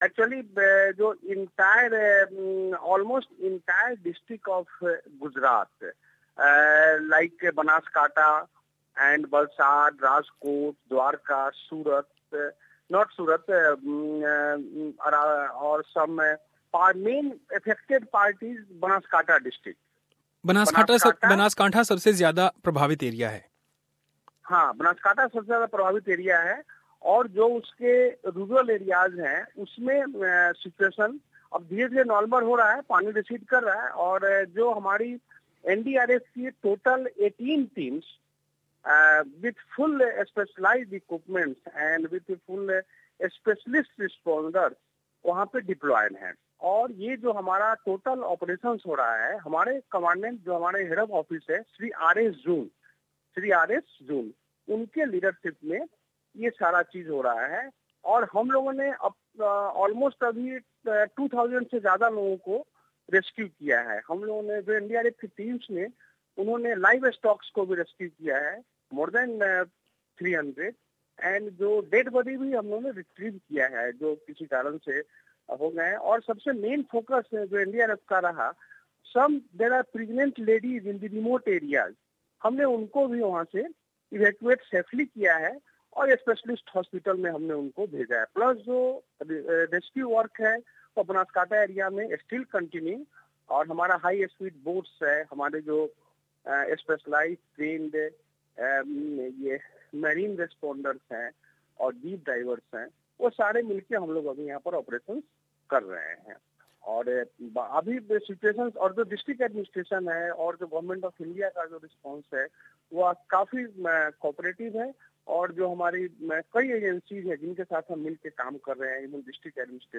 Source: Supplied You can listen to this interview by clicking on the play button above.